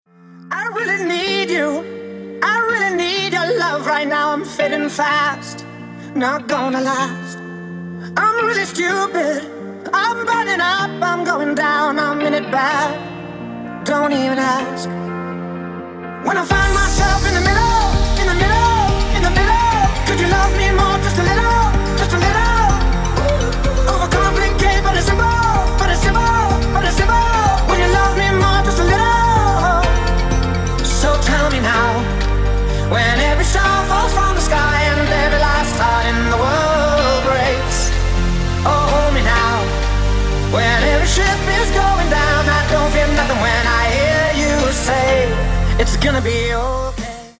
• Качество: 160, Stereo
поп
мужской вокал
dance
future house